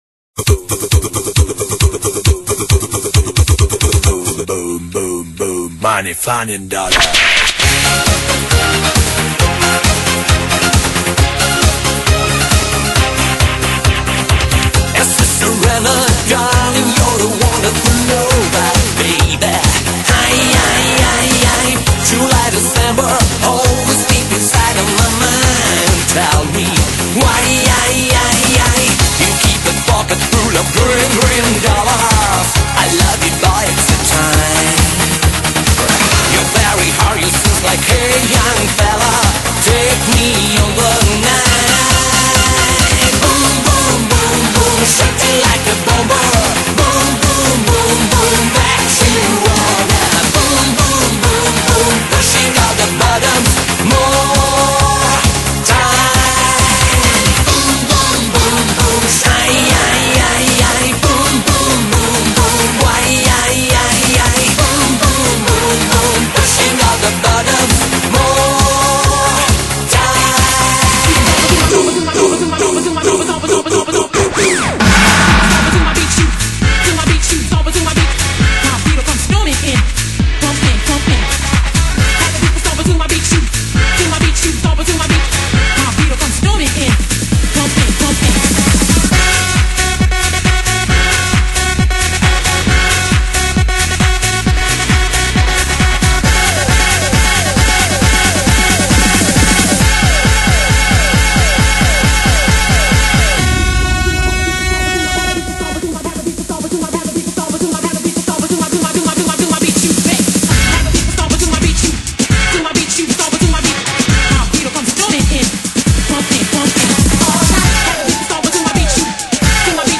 BPM130-190
Audio QualityPerfect (High Quality)
CommentsGet in gear with this driving techno mix of classic